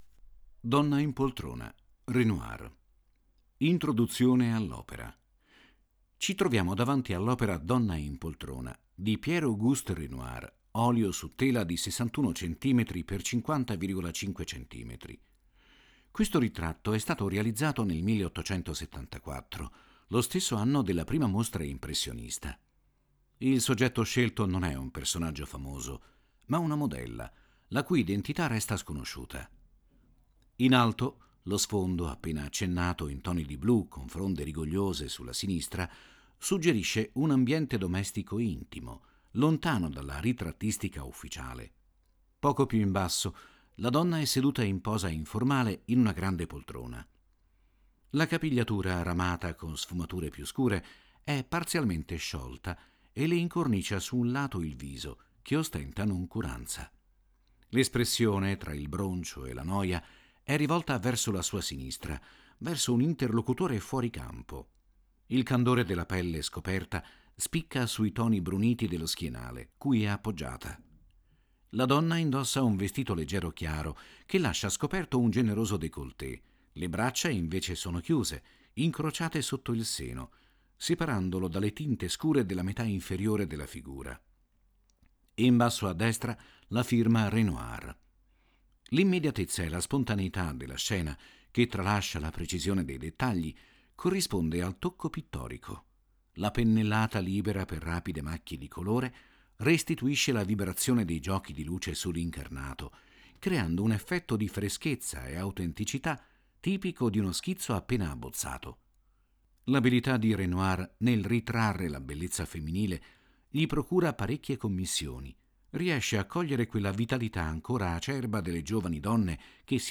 • 7 AUDIODESCRIZIONI DELLE OPERE che accompagnano il visitatore nell’esplorazione delle opere più significative della mostra, fruibili tramite QR code